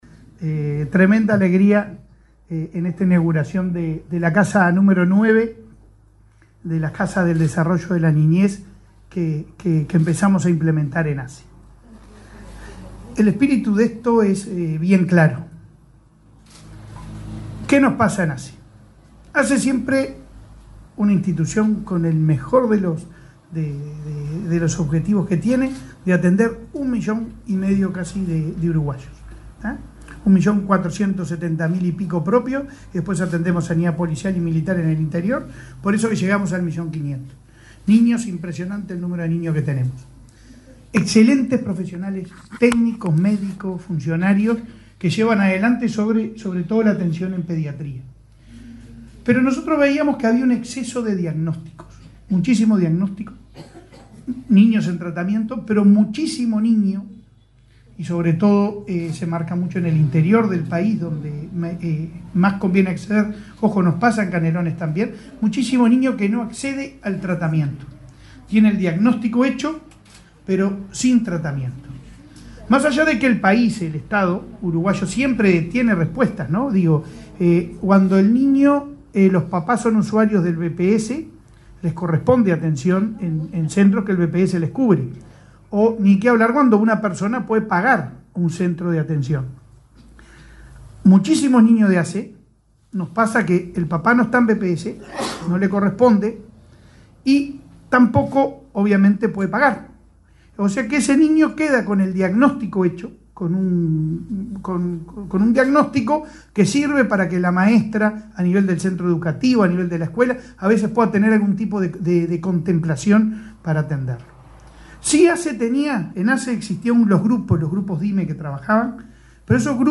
Palabras del presidente de ASSE, Leonardo Cipriani
Palabras del presidente de ASSE, Leonardo Cipriani 07/05/2024 Compartir Facebook X Copiar enlace WhatsApp LinkedIn El presidente de la Administración de los Servicios de Salud del Estado (ASSE), Leonardo Cipriani, participó, este martes 7 en Ciudad de la Costa, Canelones, en la inauguración de una casa del desarrollo de la niñez en esa localidad.